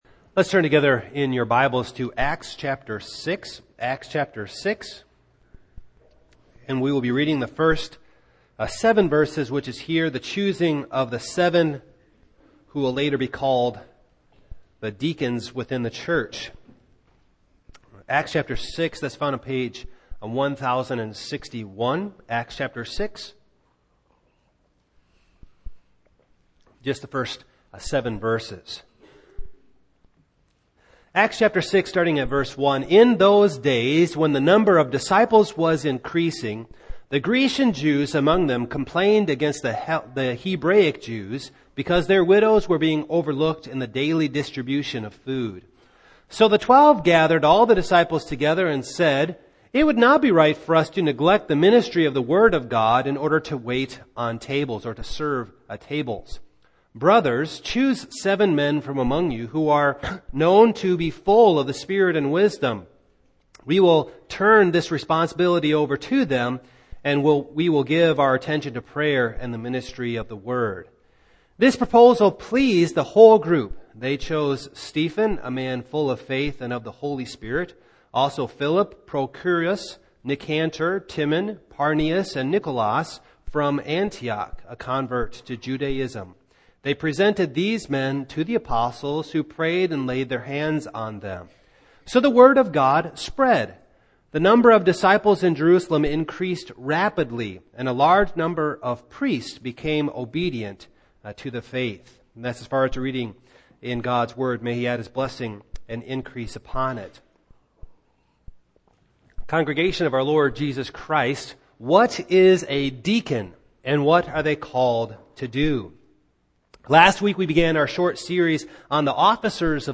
Single Sermons
Service Type: Morning